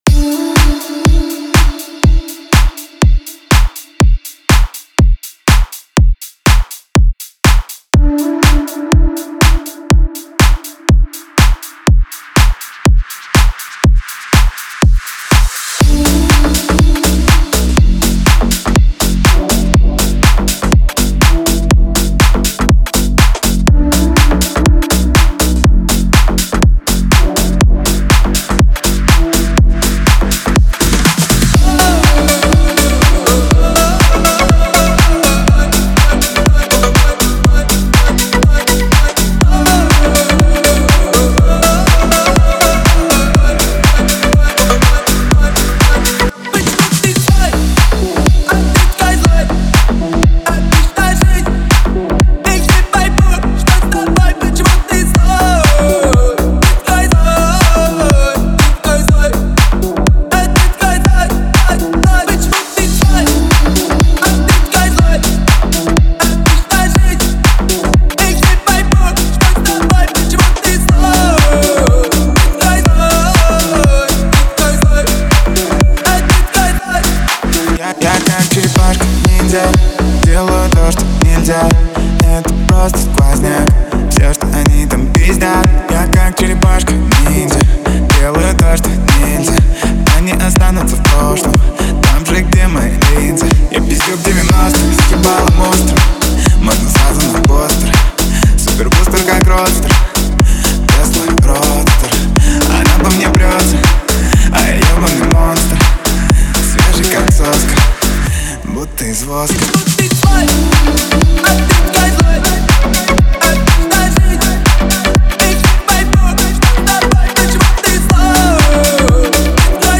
Жанр: Club-House